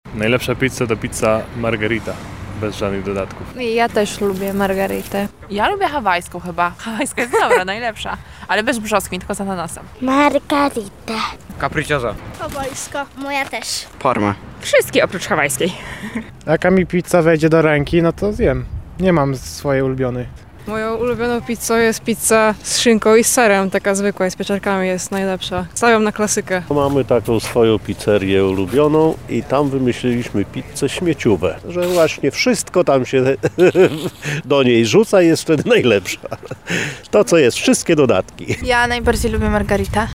Zapytaliśmy mieszkańców Lublina, jaki jest ich ulubiony rodzaj włoskiego dania:
SONDA Dzień Pizzy